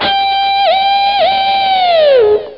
Strat Bender Sound Effect
strat-bender.mp3